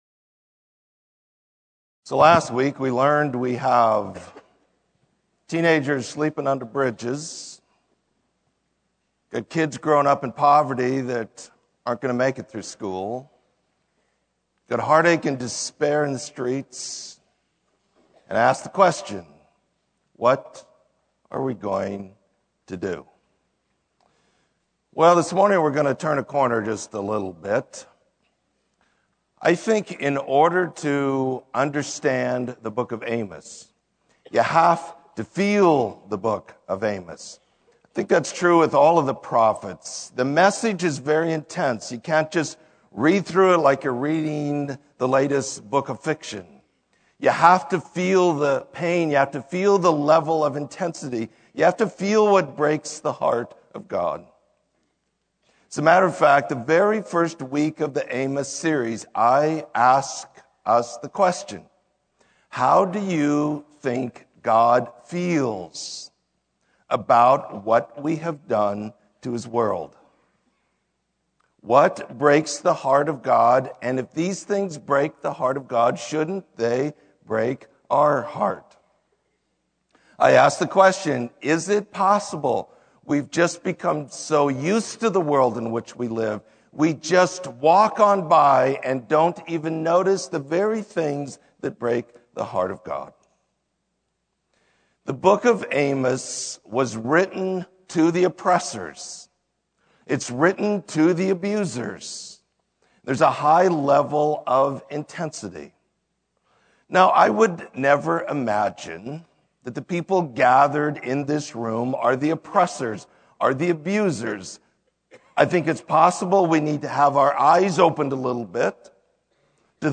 Sermon: Let Justice Flow